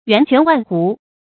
源泉万斛 yuán quán wàn hú 成语解释 比喻文思涌溢。